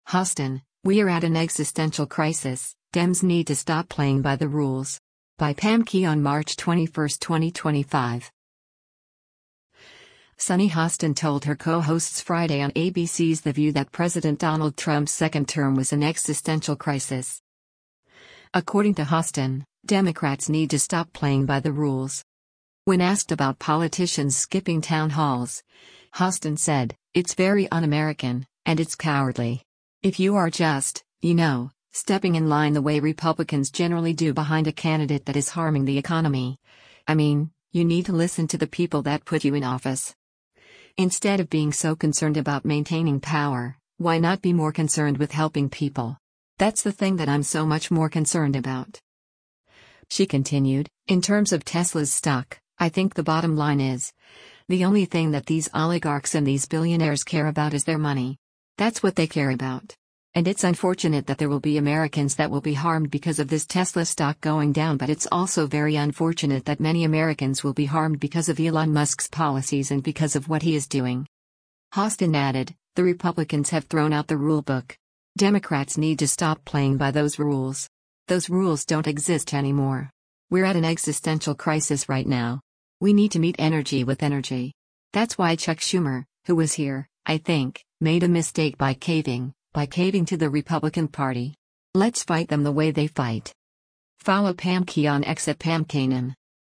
Sunny Hostin told her co-hosts Friday on ABC’s “The View” that President Donald Trump’s second term was an “existential crisis.”